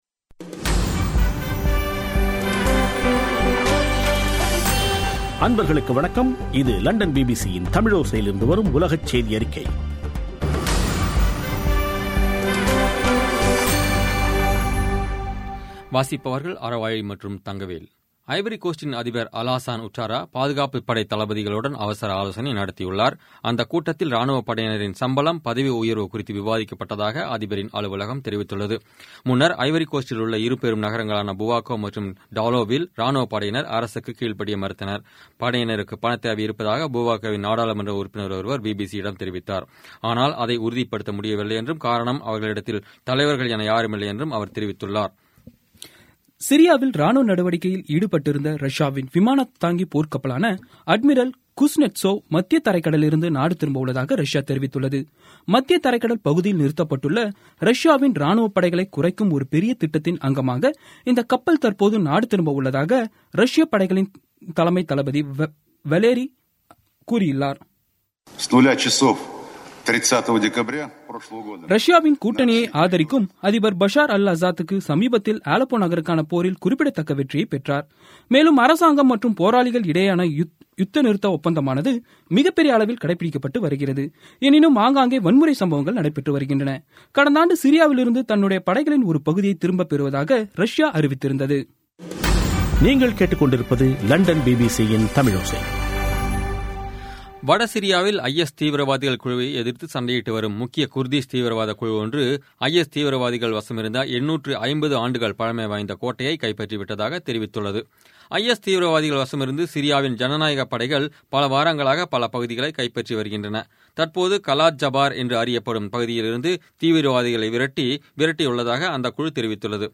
பி பி சி தமிழோசை செய்தியறிக்கை (06/01/17)